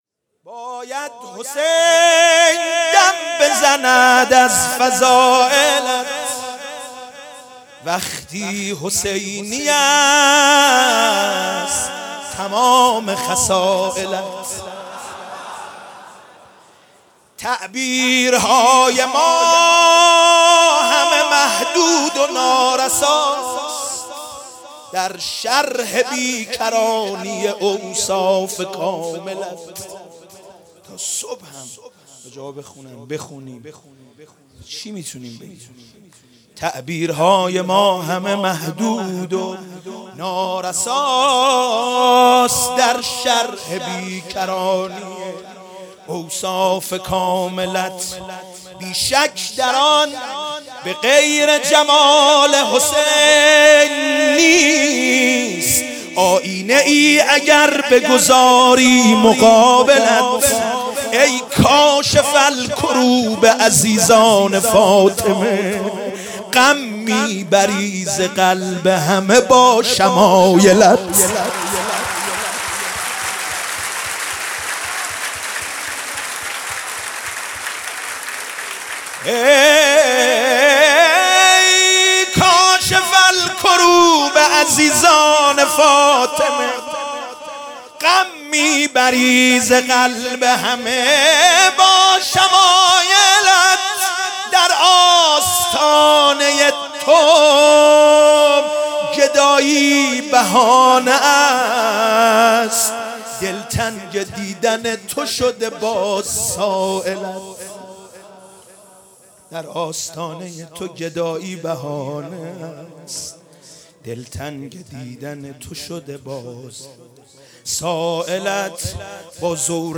ولادت سرداران کربلا
مدح مولودی